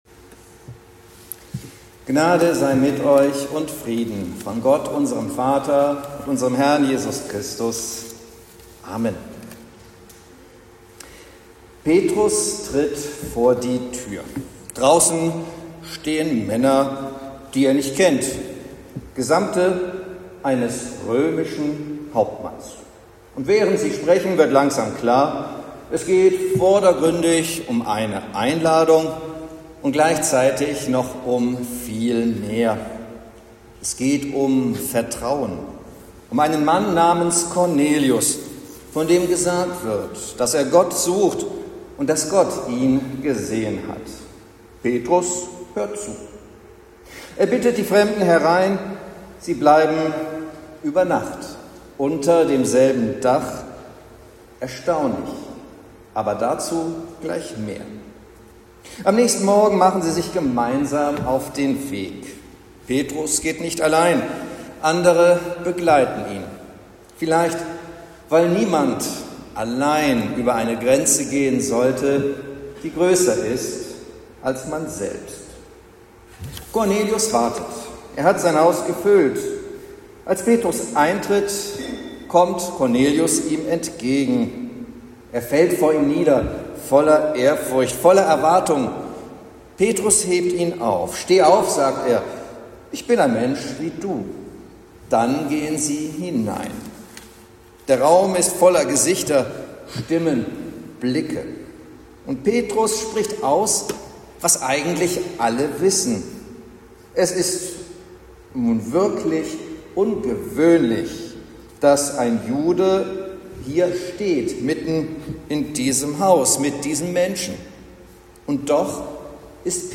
Predigt zum 3. Sonntag nach Epiphanias